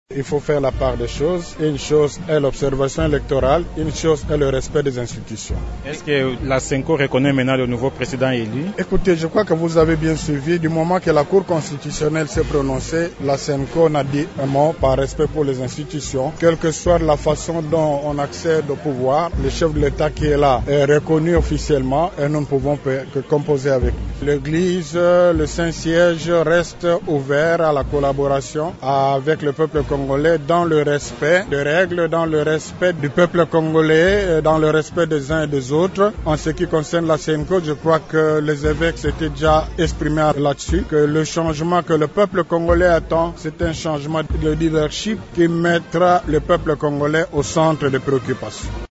tous présents à la cérémonie d’échange des vœux du Chef de l’Etat avec le corps diplomatique à la cité de l’OUA.